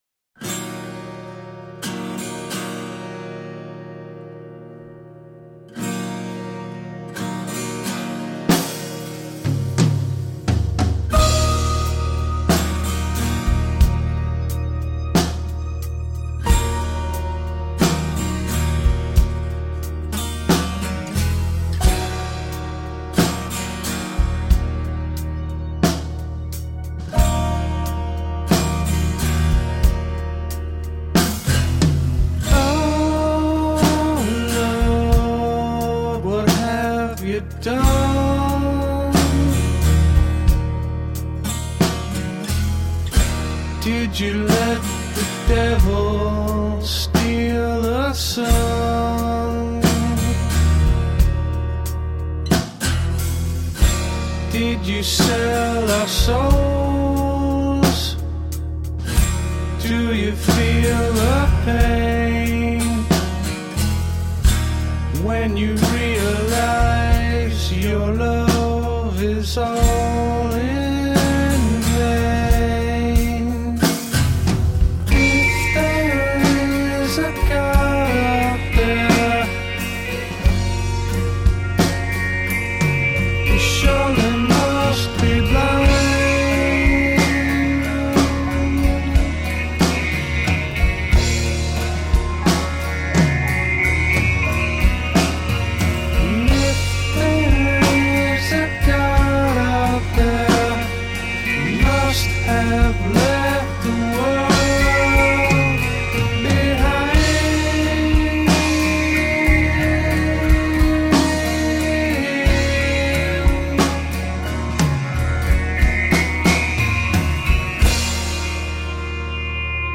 Moody melodic prog rock.
autumnal and melancholy
Tagged as: Alt Rock, Folk-Rock, Prog Rock